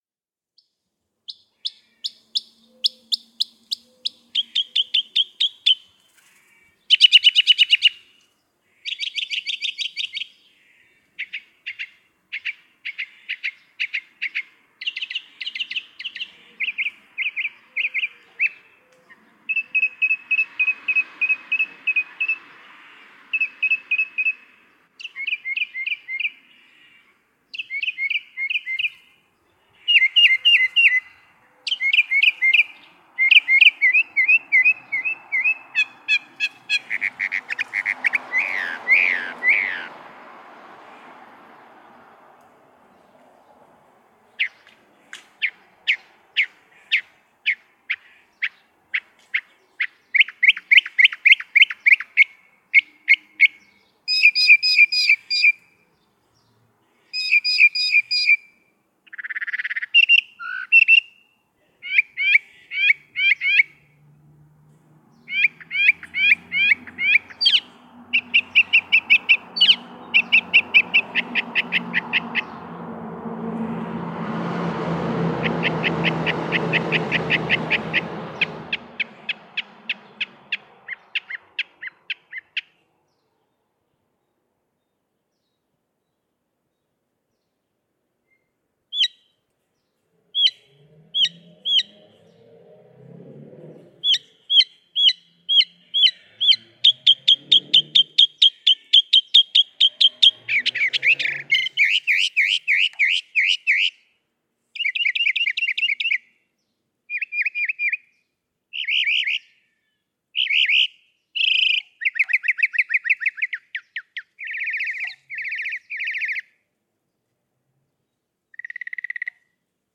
A northern mockingbird
The species is known for its ability to mimic bird calls and other types of sound, including artificial and electronic noises.